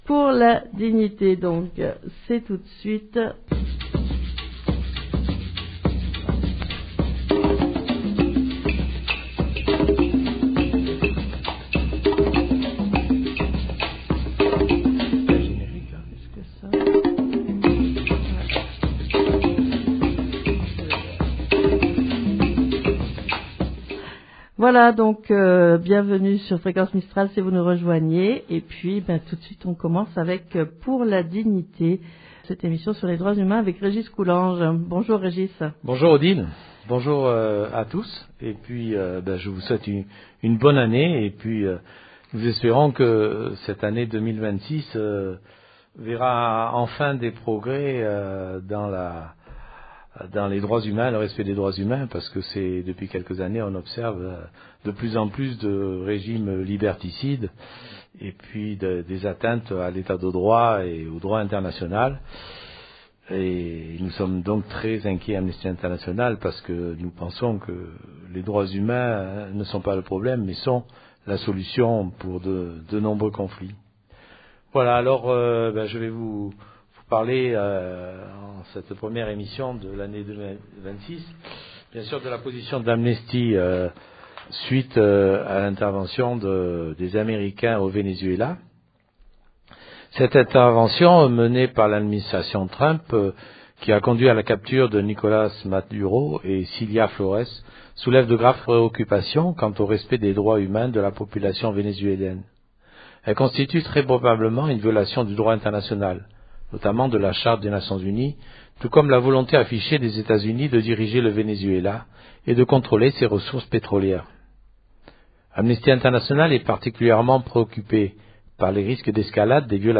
Amnesty s’inquiète des violations des droits humains suite à l’intervention militaire américaine au Venezuela Chanson du Venezuela